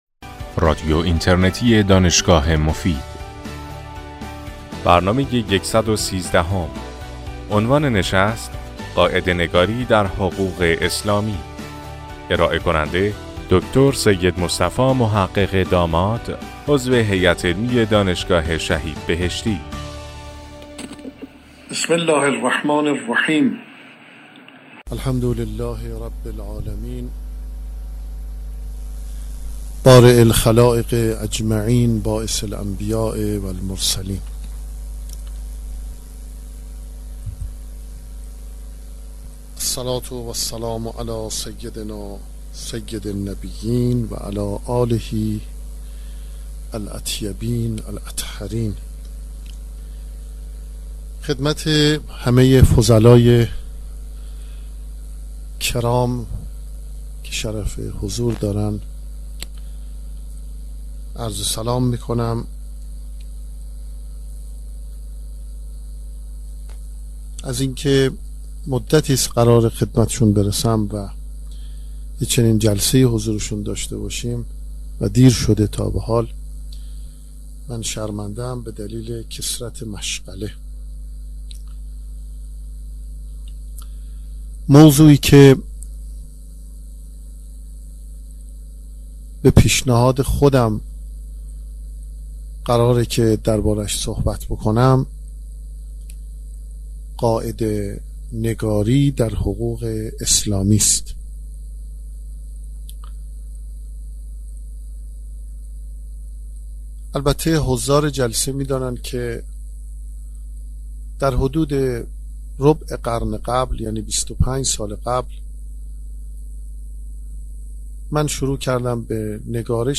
دکتر محقق داماد در این سخنرانی ضمن بیان اقدامات تاریخی نحله های مختلف اسلامی برای تدوین روشمند قواعد فقهی بر لزوم قاعده نگاری های جدید در رابطه با نظام حقوق اسلامی تاکید می نمایند.